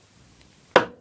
工具 " 锤击1
描述：几个击中（在木头上）用中型锤子。录制第四代iPod touch，然后使用media.io转换为.wav